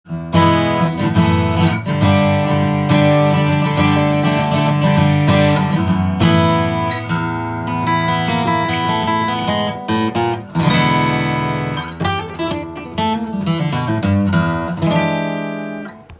試しに弾かせて頂きました。
コードストロークをしてみると煌びやかさと音の暖かさが最高です。
録音してみると　すでにEQをいじったような音抜けがします。